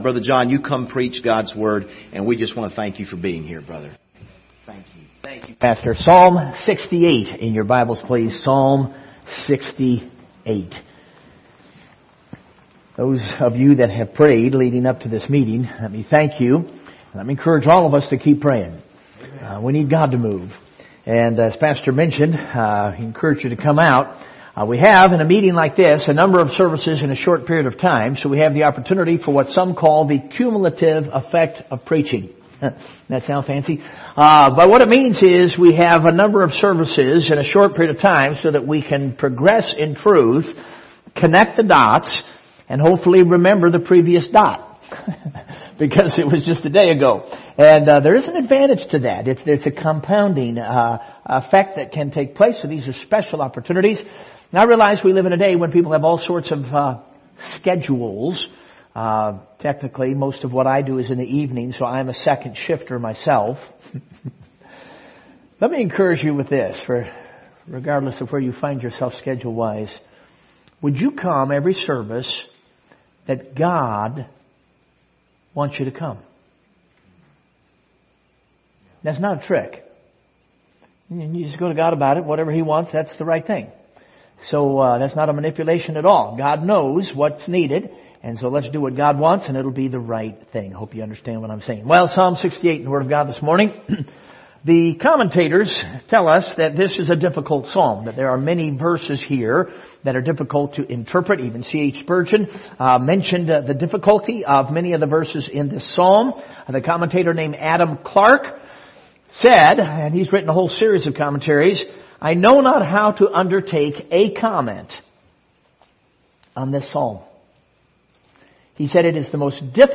2023 Winter Revival Passage: Psalm 68:1-3 Service Type: Sunday Morning View the video on Facebook Topics